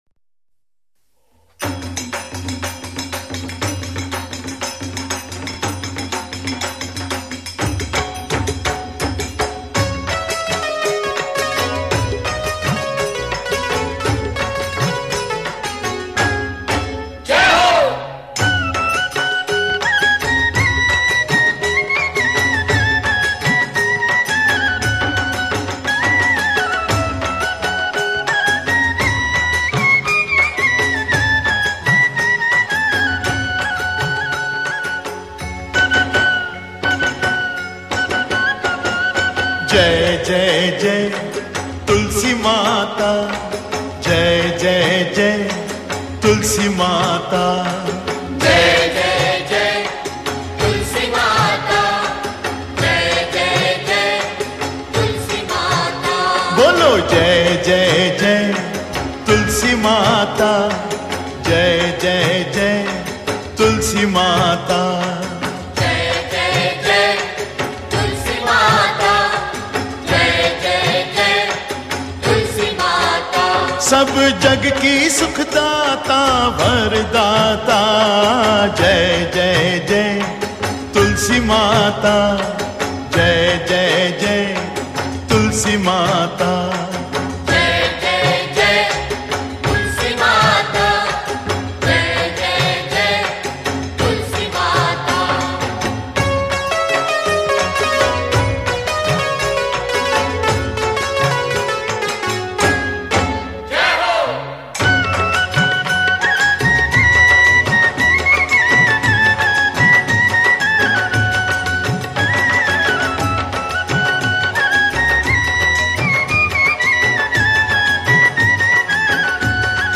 Devotional Single Songs - Bhajans